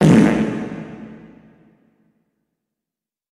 fart.wav